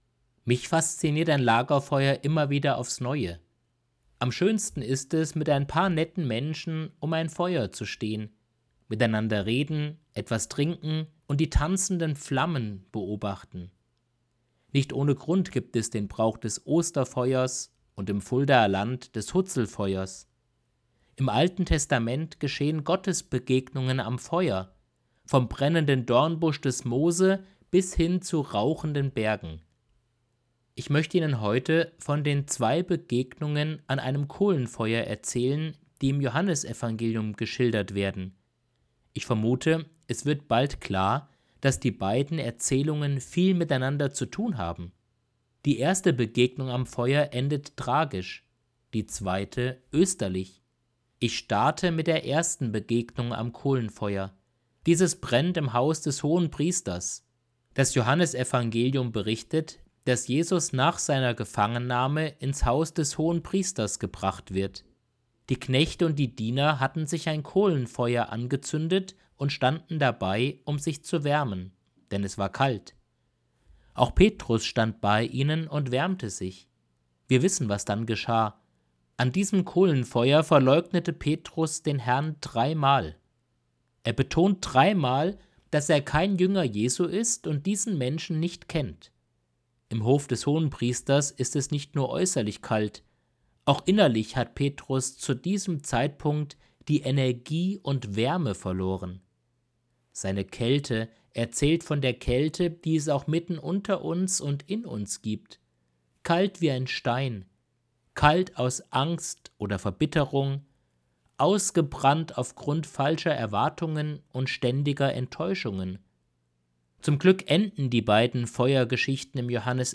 Predigt vom 04.05.2025